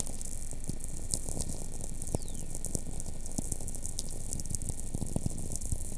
Heidelberg, Germany